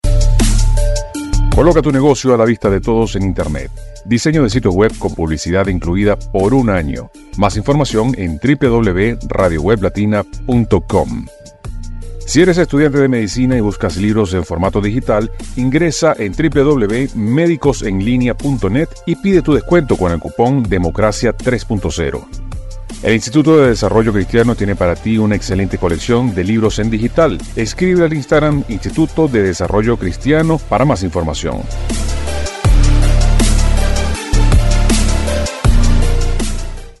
Servicio de Grabación (Voz en OFF)